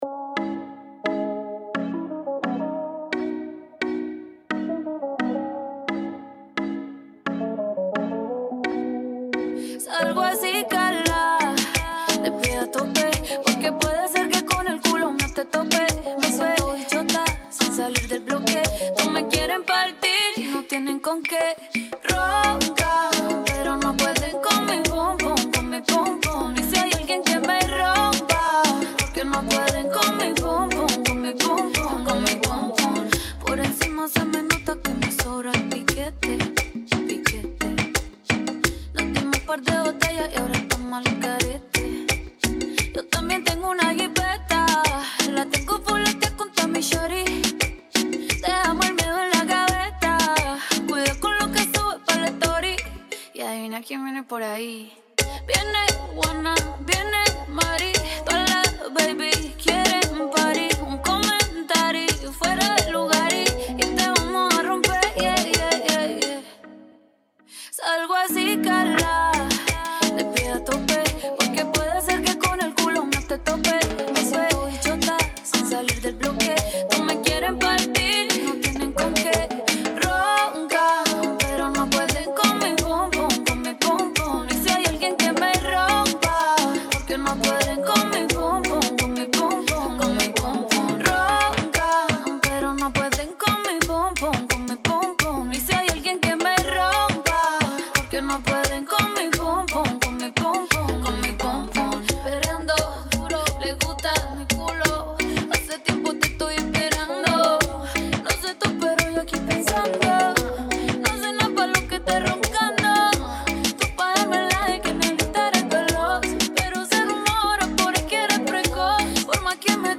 87 BPM
Genre: Salsa Remix